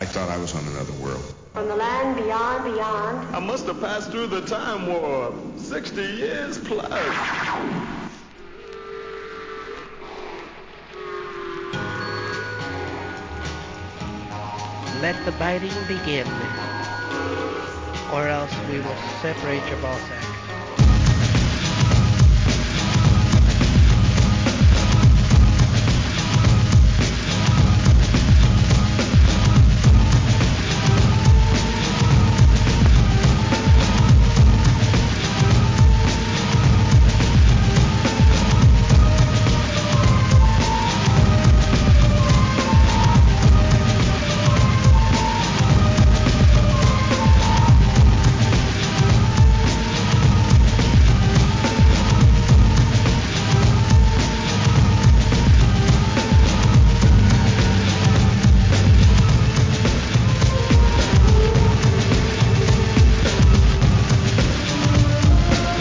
HIP HOP/R&B
ブレイクビーツ・アルバム!!